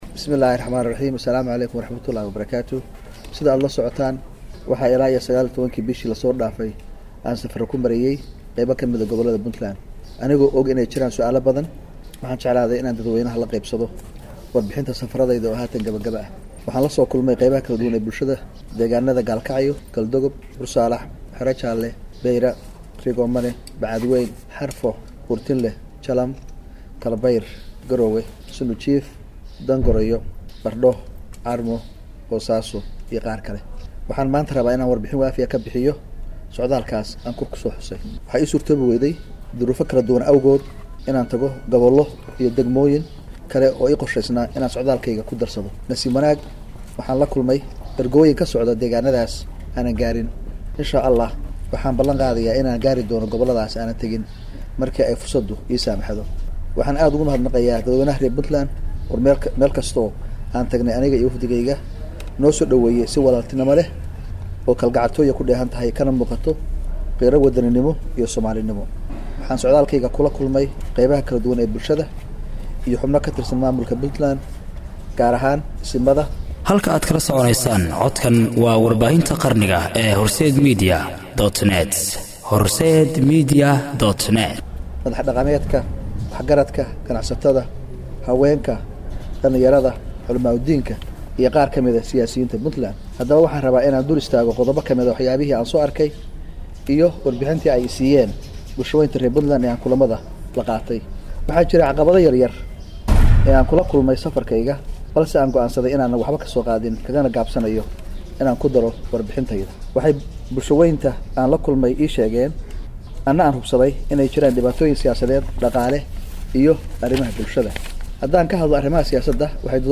Shir-Saxaafaeedkii-Pf-Cabdiwali-Maxamed-Cali-Gaas-Horsed-.mp3